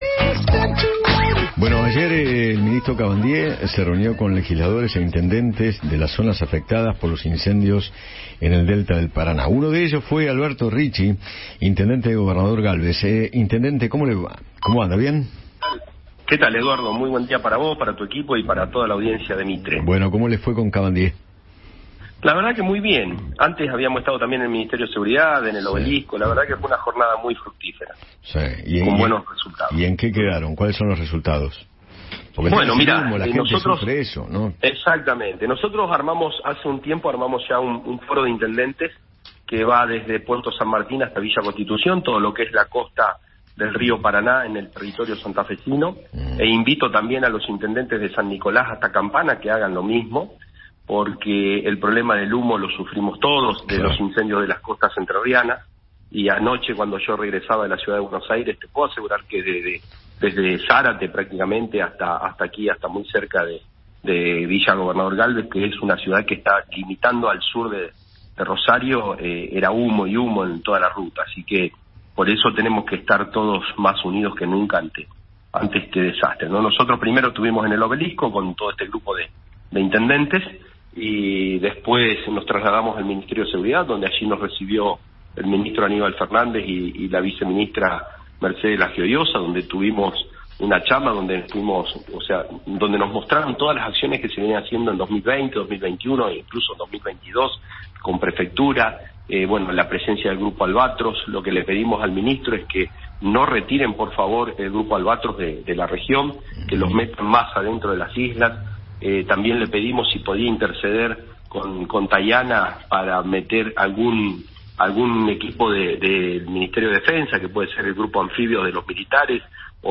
Alberto Ricci, intendente de Villa Gobernador Galvez, habló con Eduardo Feinmann sobre la reunión que mantuvo con el ministro de Ambiente de la Nación, Juan Cabandié, para encontrar una solución a los incendios forestales, en la zona del Paraná.